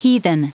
Prononciation du mot heathen en anglais (fichier audio)
Prononciation du mot : heathen